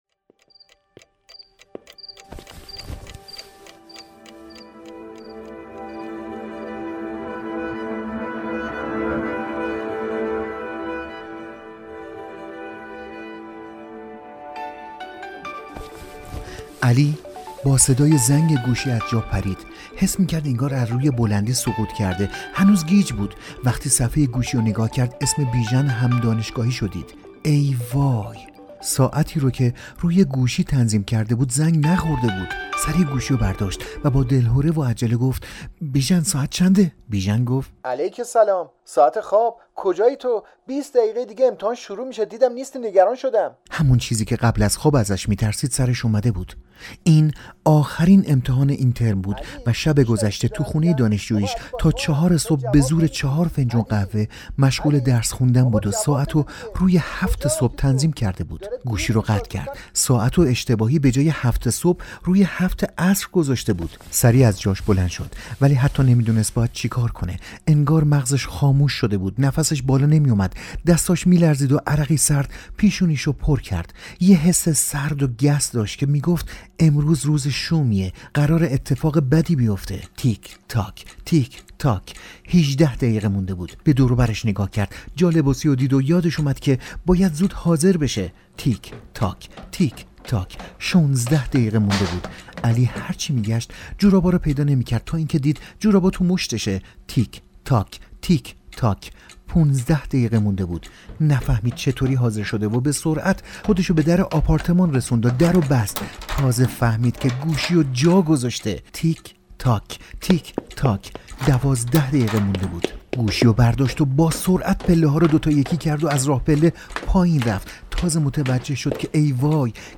نمونه ای از سری پادکستهای آموزشی و تبلیغاتی در حوزه مشاوره و روانشناسی به سفارش سایت هوکات.